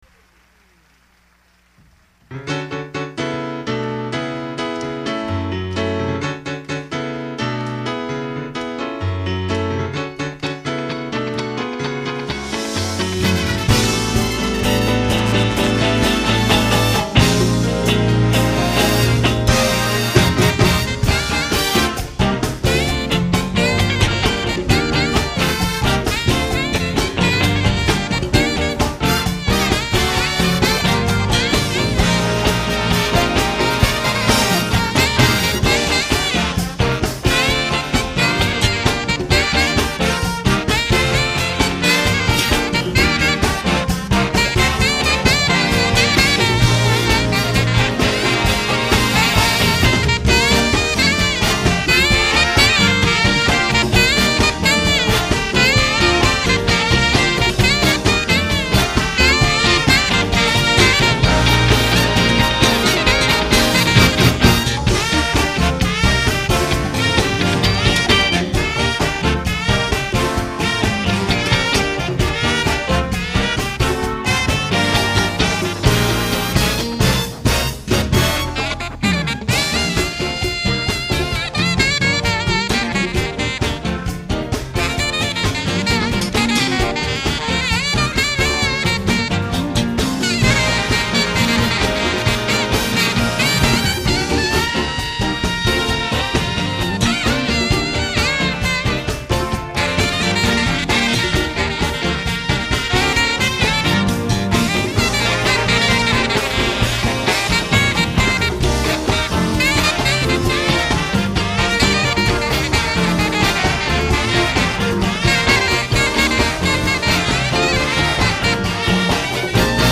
원곡보다 더 신나네요~~